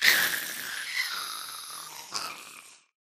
Sound / Minecraft / mob / ghast / death.ogg